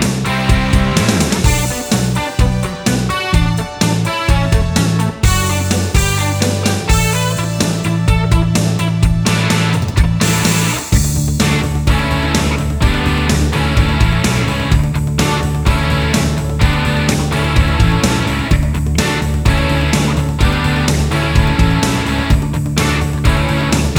no Backing Vocals Rock 3:55 Buy £1.50